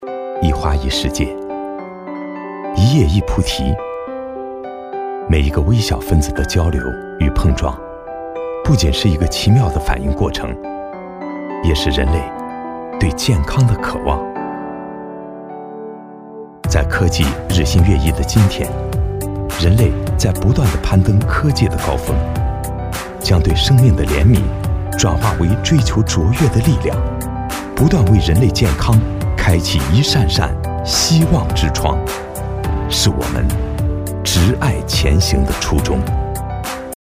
科技感男162号（清新自然
科技感 电子科技宣传片配音
清新自然，磁性男音，偏中年音。